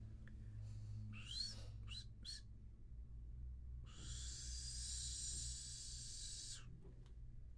卡通幻灯片的声音效果
描述：卡通音效用我的竹滑哨记录下来
Tag: SoundEffect中 卡通 SoundEffect中 移动的 愚蠢的 幻灯片 吹口哨 滑动 效果 FX 滑动啸叫 搞笑 声音